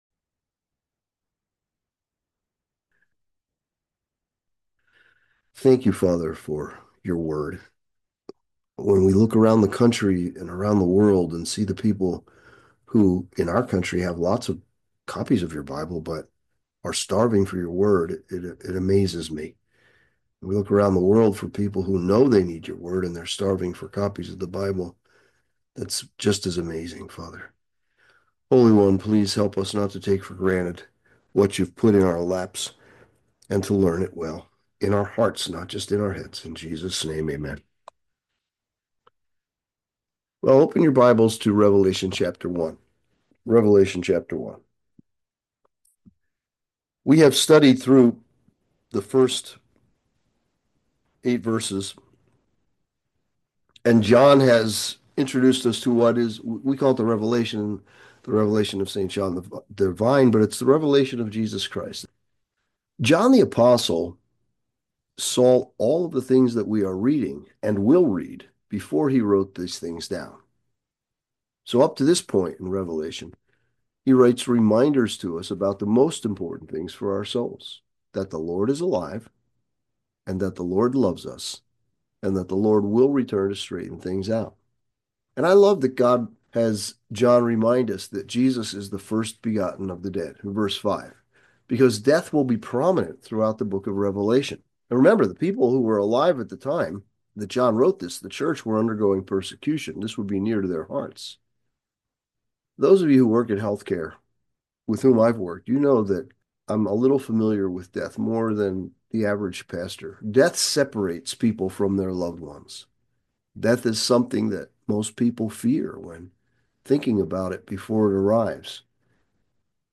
Sermons | Calvary Chapel on the King's Highway